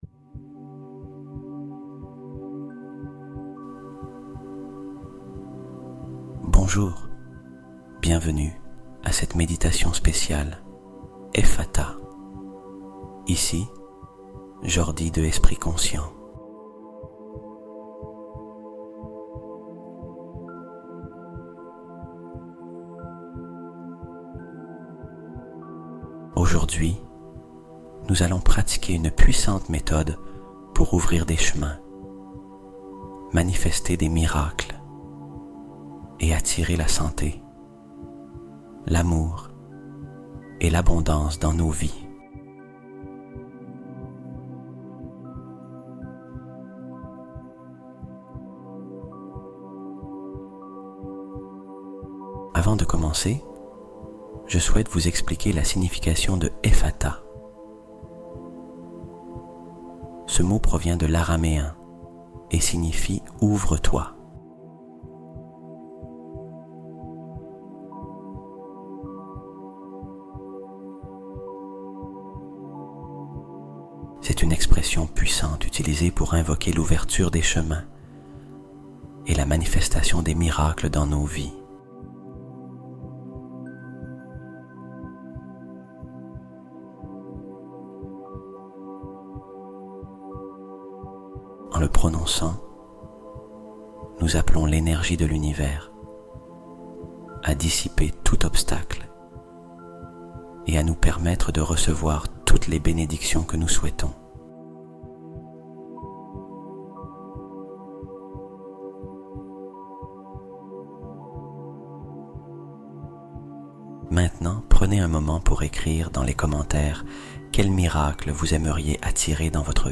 EFFATÁ : Le Mot Qui Débloque Miracles et Richesses Instantanément | 432Hz Fréquence Divine
Méditation Guidée